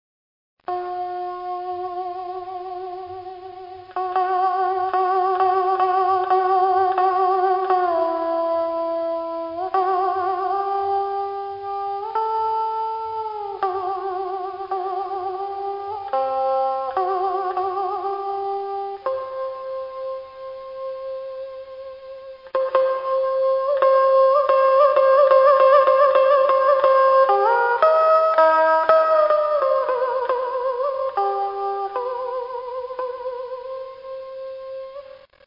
Dan Bau
Vietnamese Traditional Instruments
danbau.wav